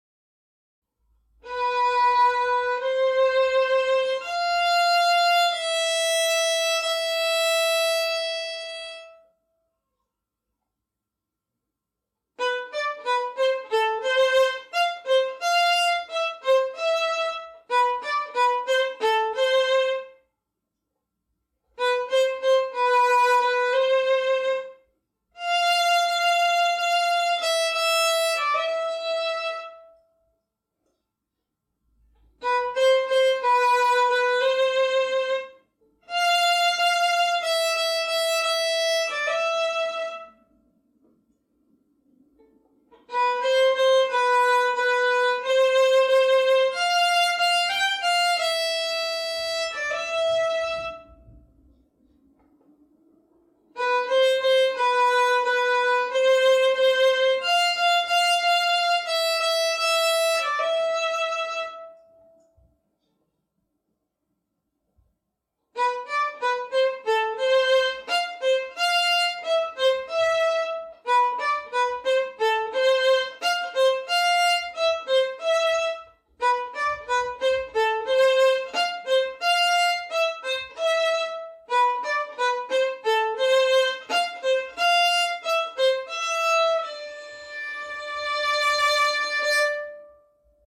• violin solo